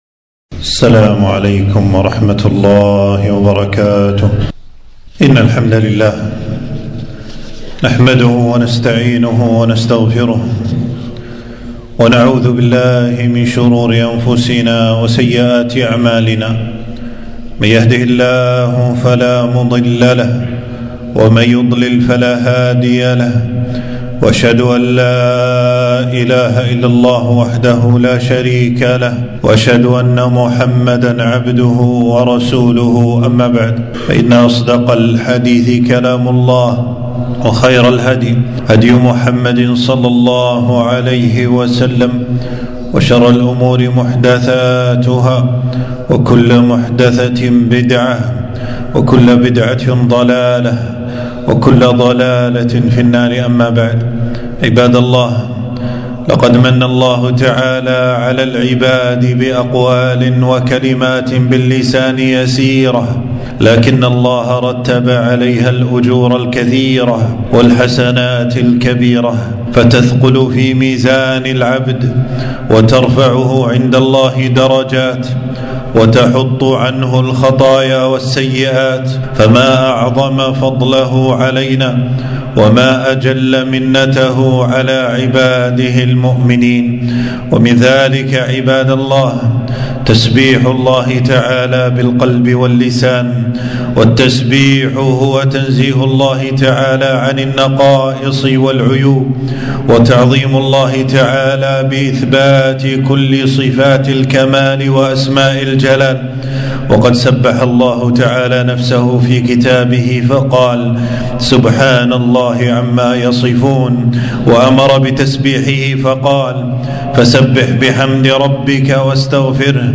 خطبة - فضائل تسبيح الله ومنزلته